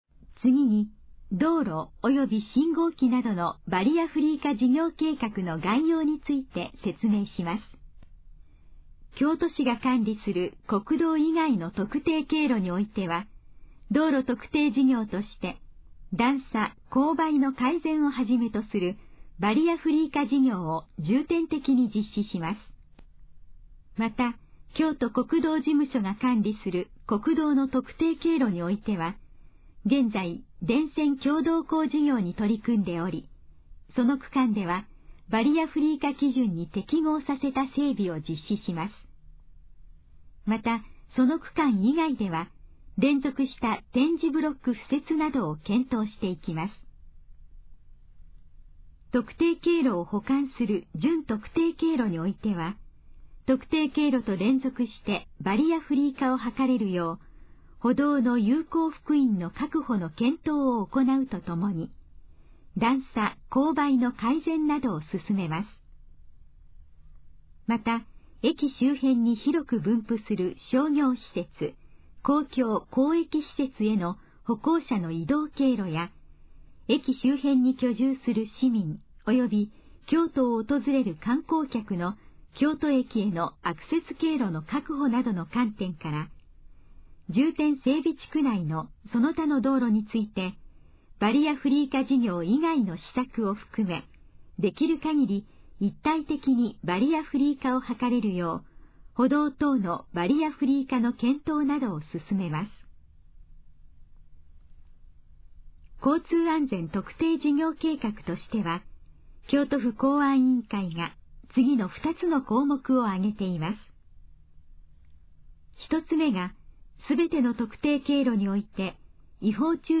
以下の項目の要約を音声で読み上げます。
ナレーション再生 約283KB